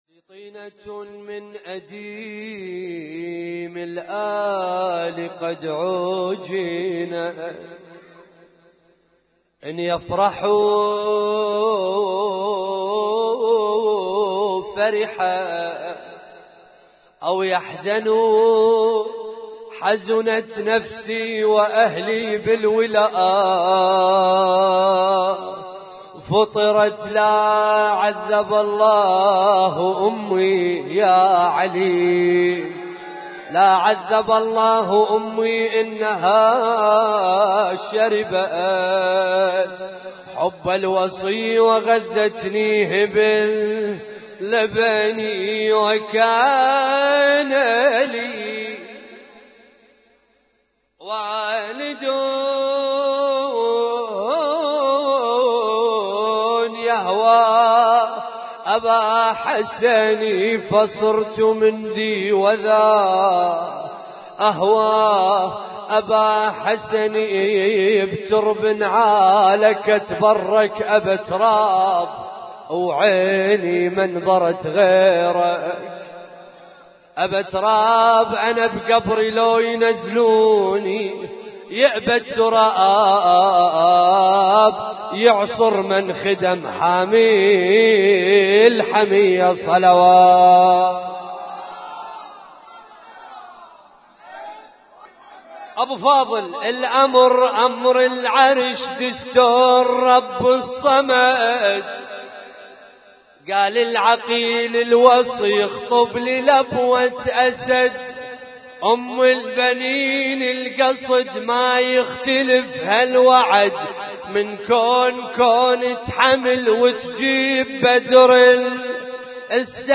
اسم التصنيف: المـكتبة الصــوتيه >> المواليد >> المواليد 1437